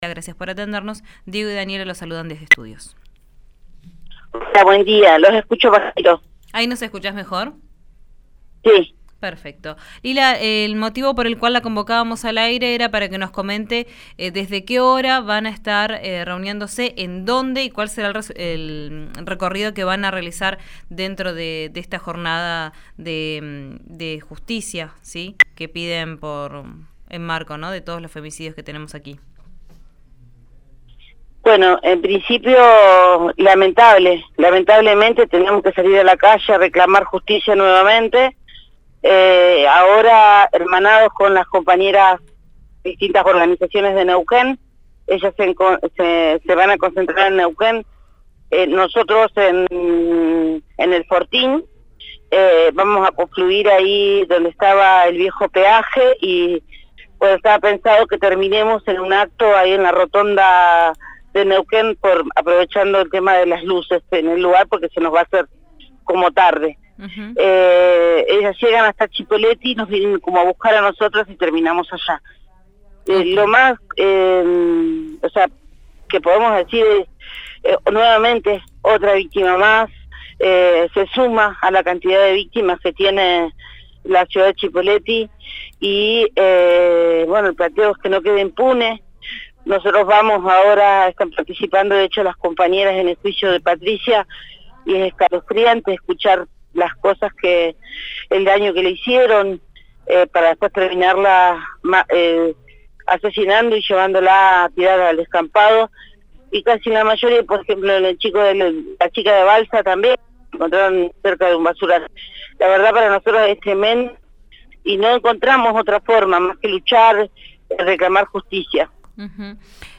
En comunicación con «Vos al aire» aseguró: “Nuevamente otra víctima más se suma a las que ya tiene la ciudad de Cipolletti” y exigió que los femicidios no queden impunes. Respecto a las actividades sobre el puente viejo aclaró que “no encontramos otra forma que reclamar y pedir justicia”.